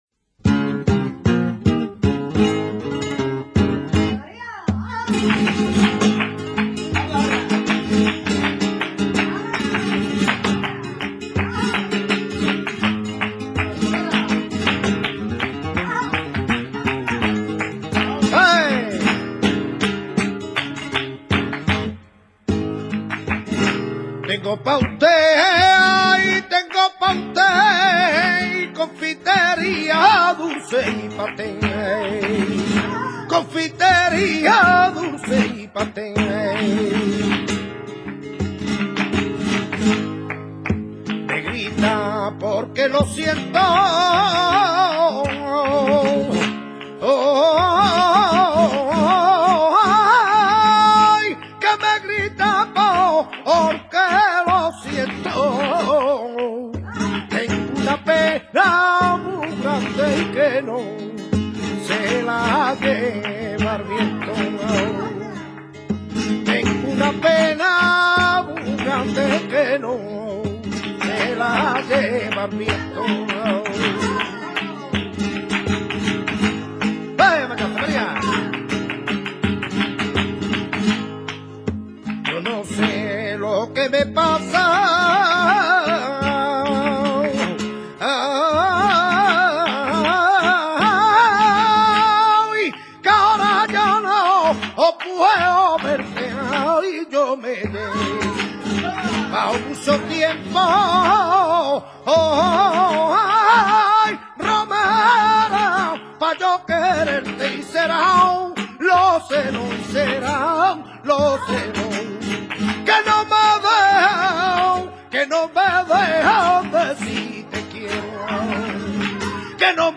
Sonidos y Palos del Flamenco
romera.mp3